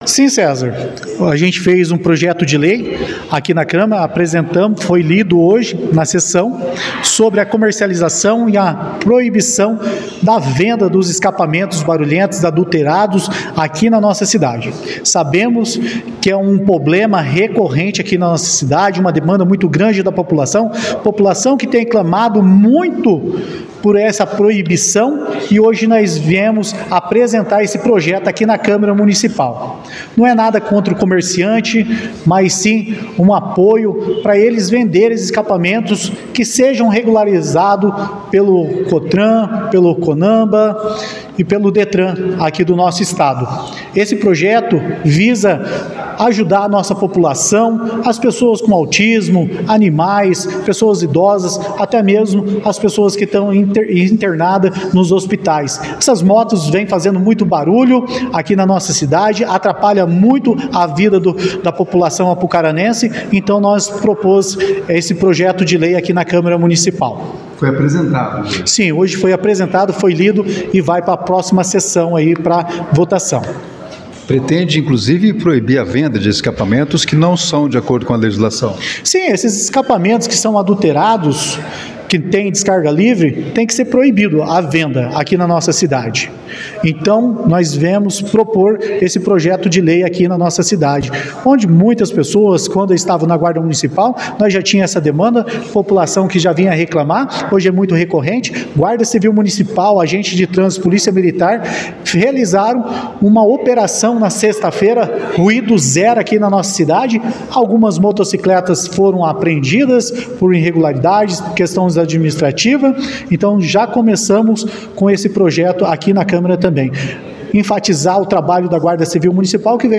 • Acompanhe as entrevistas realizadas com os vereadores após a sessão que teve a participação on-line de Odarlone Orente e as faltas justificadas, de Tiago Cordeiro e Guilherme Livoti.